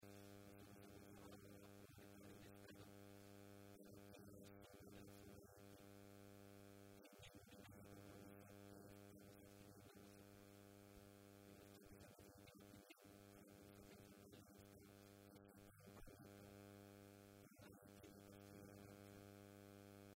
Director general de Coordinación y Planificación en reunión ITI en Guadalajara